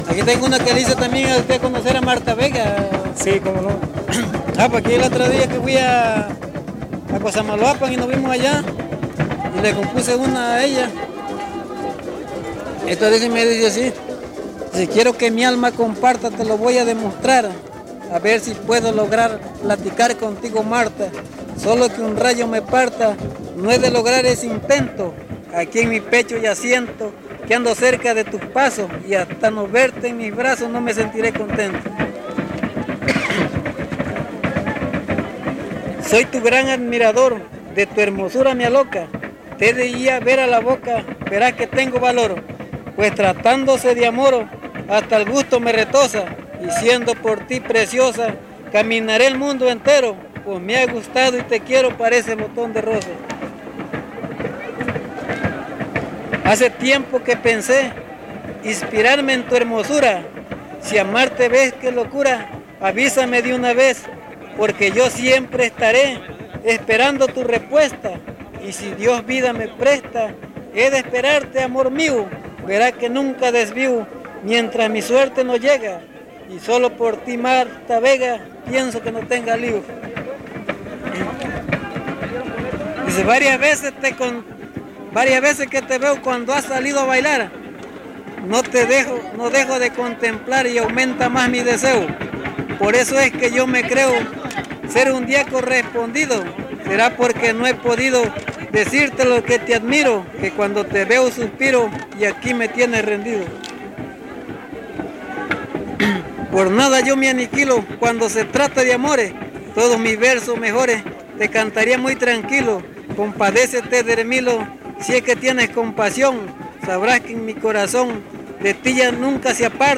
Encuentro de son y huapango